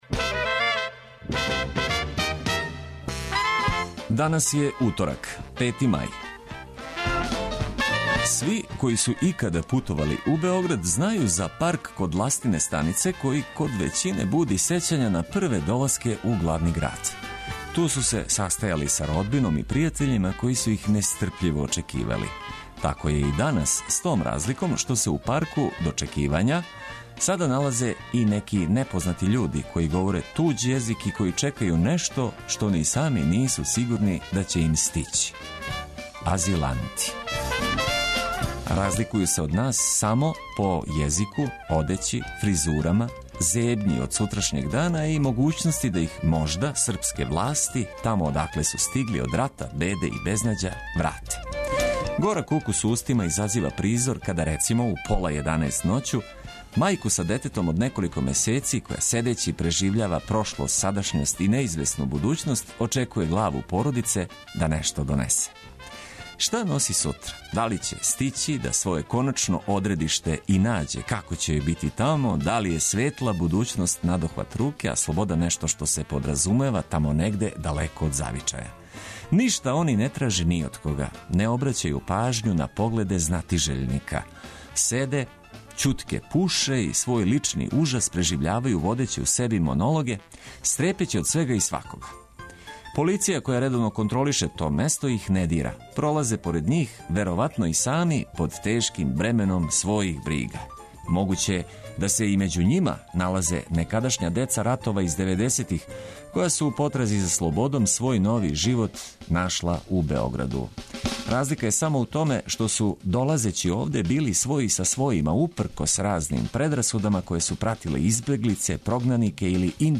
Нека дан почне уз добру музику коју ћемо прошарати информацијама од којих ћете имати користи.